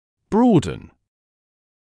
[‘brɔːdn][‘броːдн]расширять; расширяться